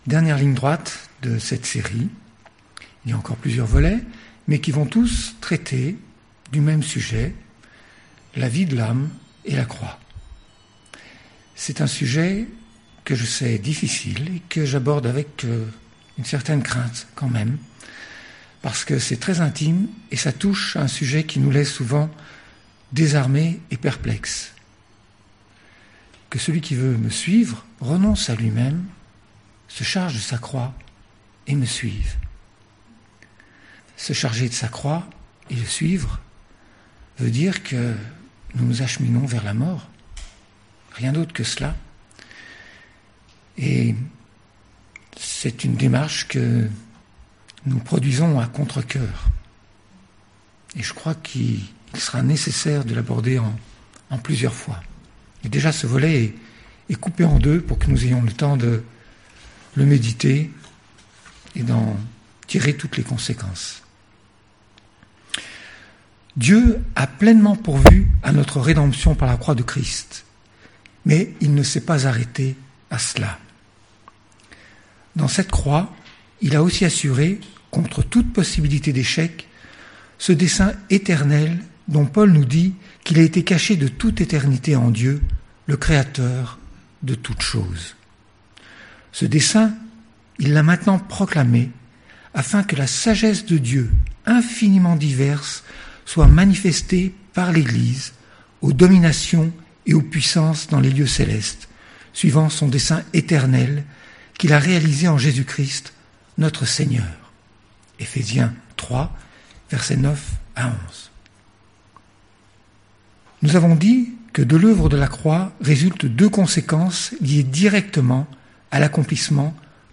Prédication